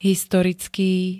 Zvukové nahrávky niektorých slov
oz7x-historicky.ogg